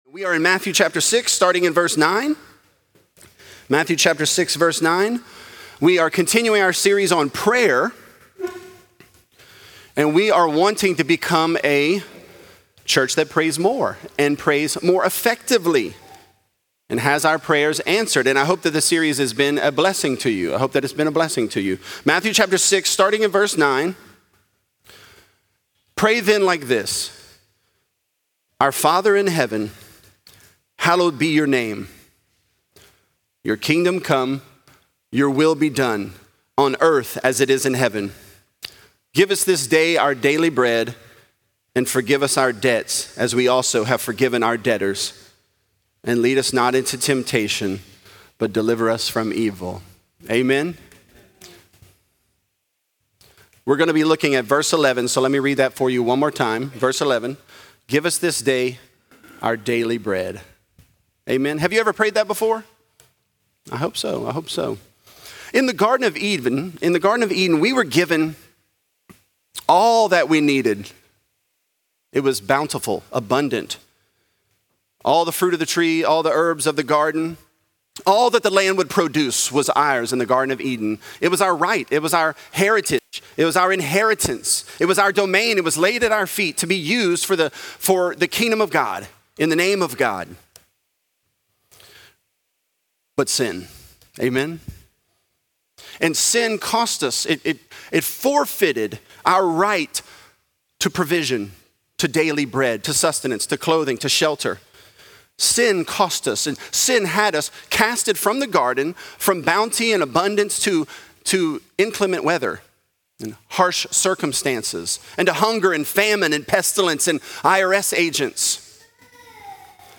Teach Us To Pray: Our Daily Bread | Lafayette - Sermon (Matthew 6)